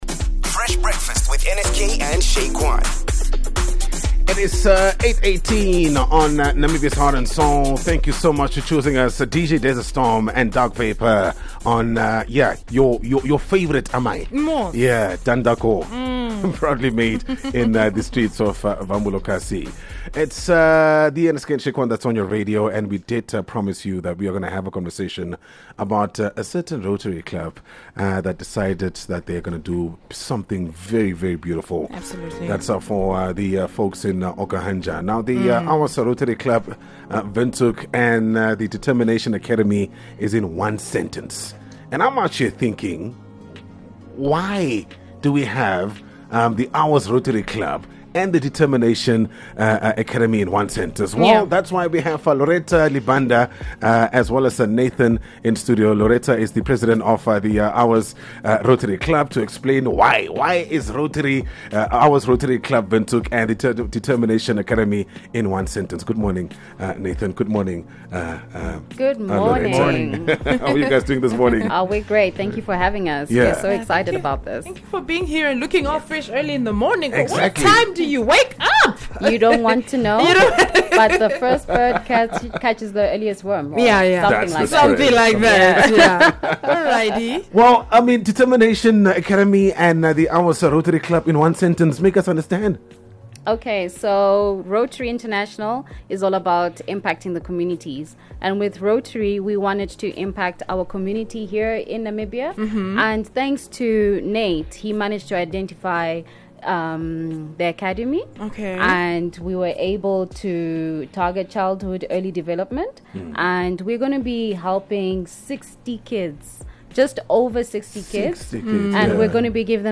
a chat about an upcoming event.